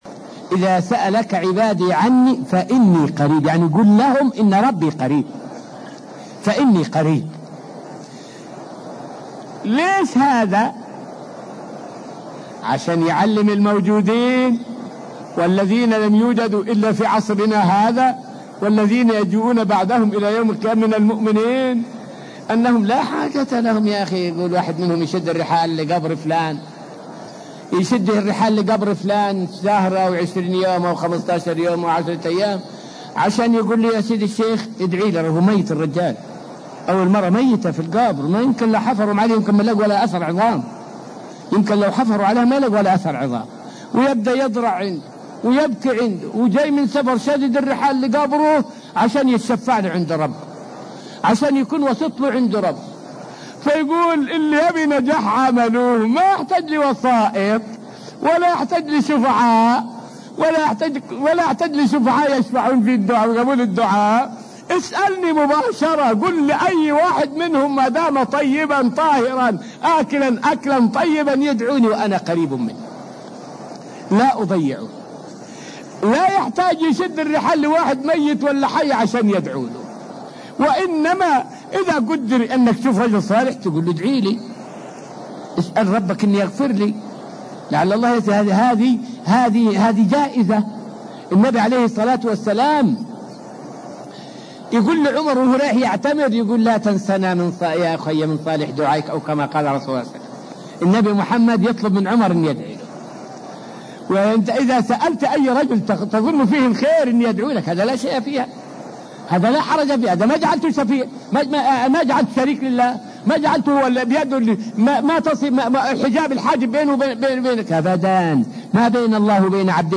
فائدة من الدرس الثالث والعشرون من دروس تفسير سورة البقرة والتي ألقيت في المسجد النبوي الشريف حول جواز طلب الدعاء من الصالحين.